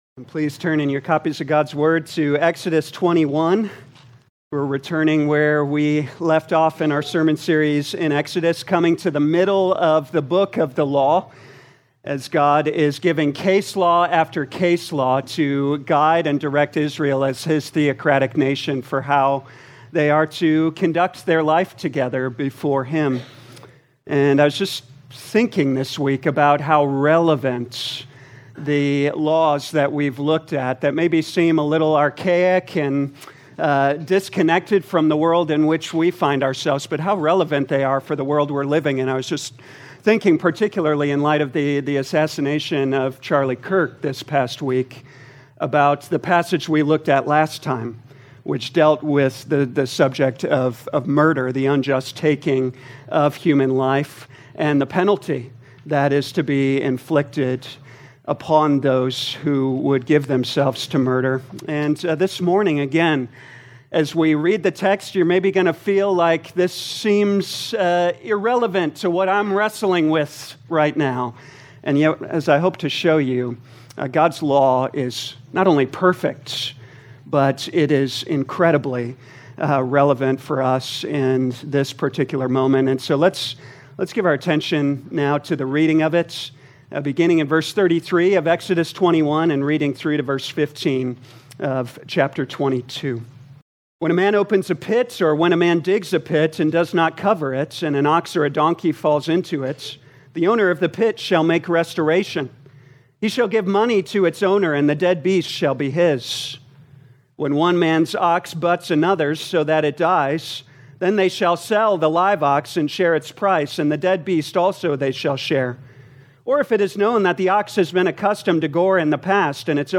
2025 Exodus Morning Service Download